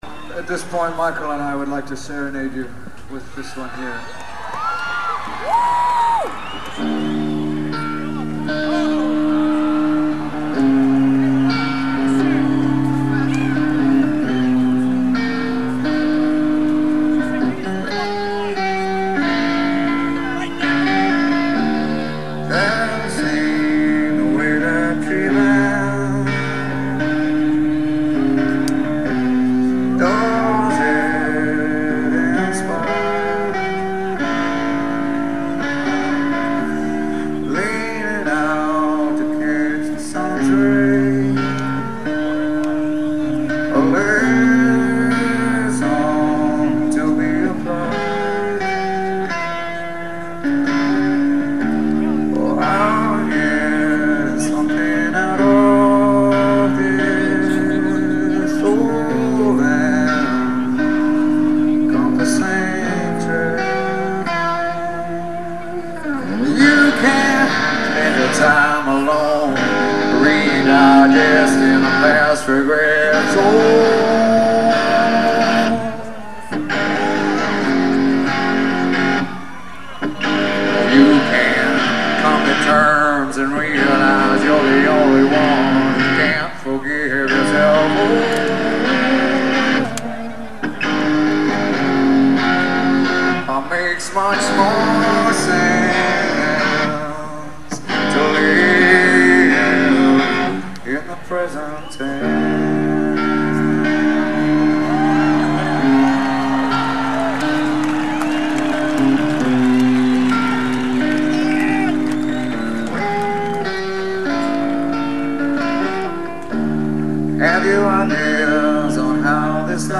Live in New York City, September 29, 1996